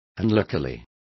Complete with pronunciation of the translation of unluckily.